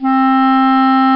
Clarinet Sound Effect
Download a high-quality clarinet sound effect.
clarinet.mp3